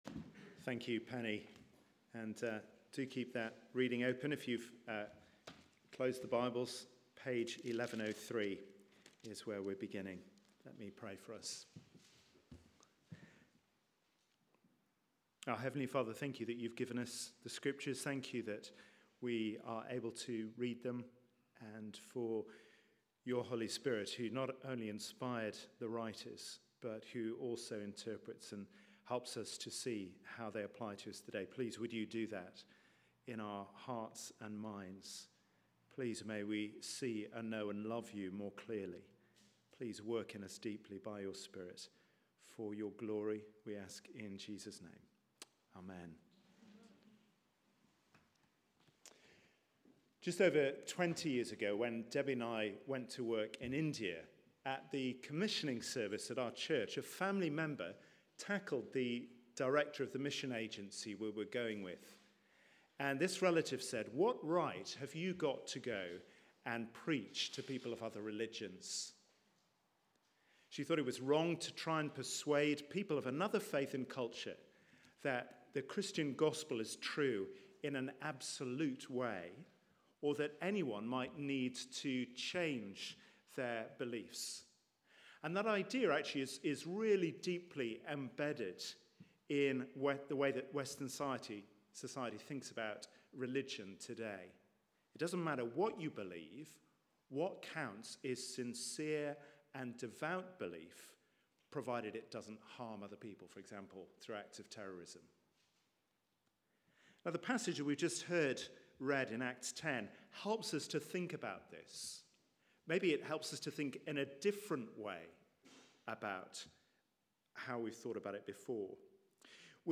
Media for 4pm Service on Sun 29th Jan 2017 16:00 Speaker
Sermon